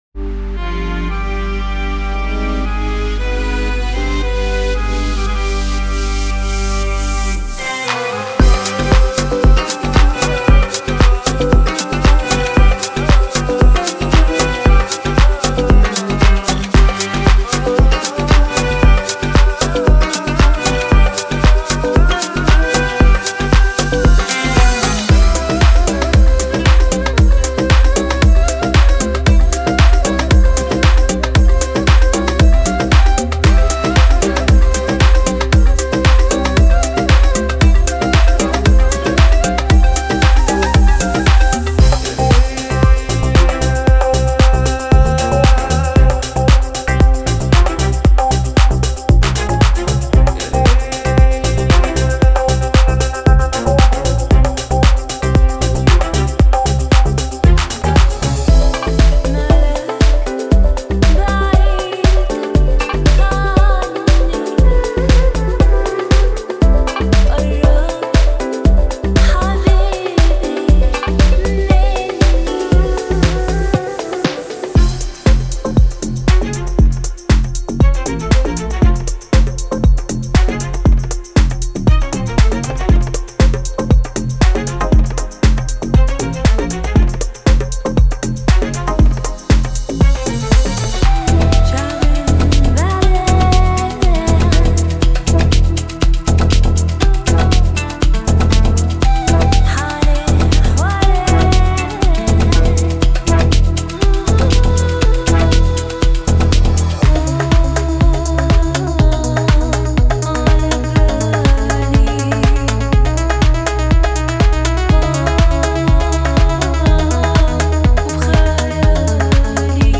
Genre:Deep House
デモサウンドはコチラ↓
Tempo/Bpm: 115-122